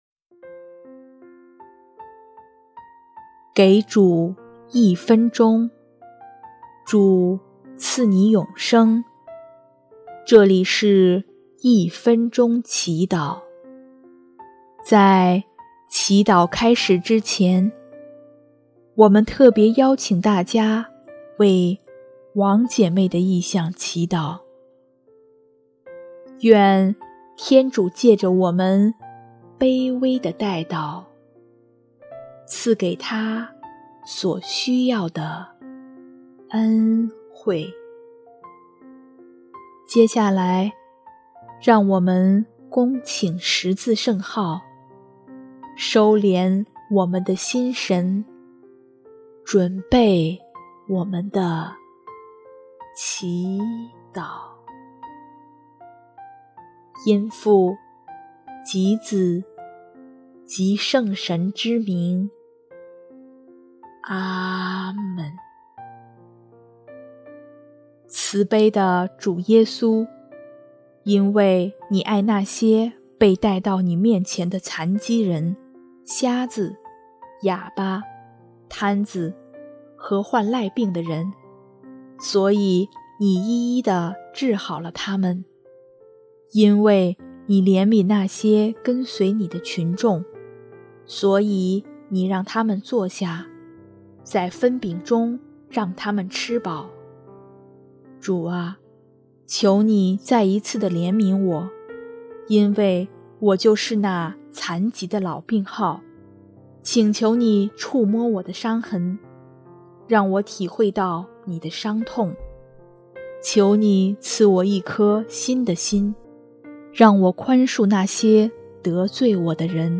音乐：主日赞歌